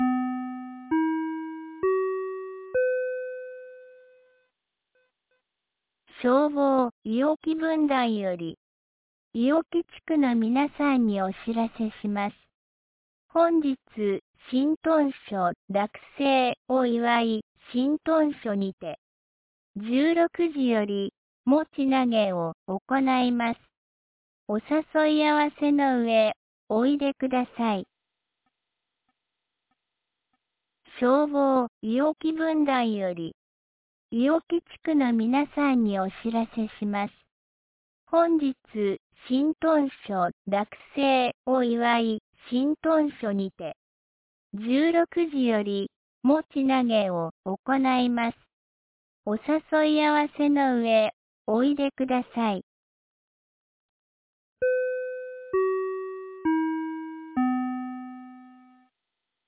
2025年04月06日 13時01分に、安芸市より伊尾木、下山へ放送がありました。